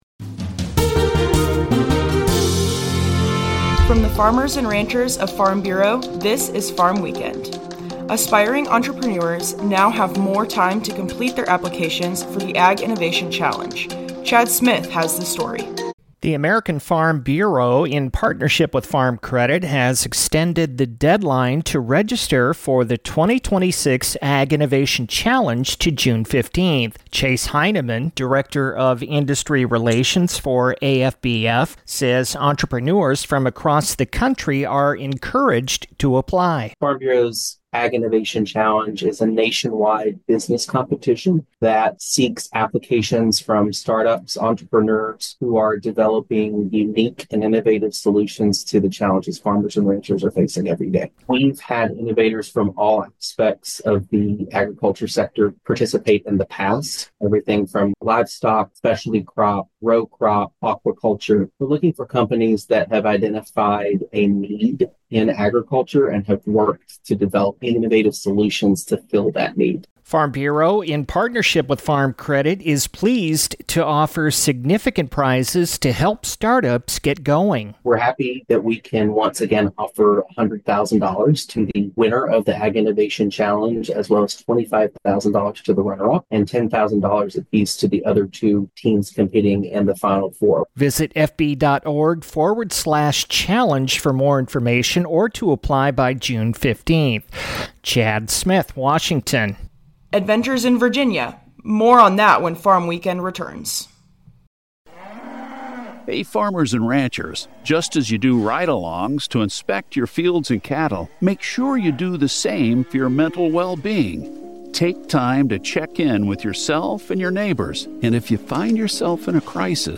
A 5-minute radio program featuring a recap of the week's agriculture-related news and commentary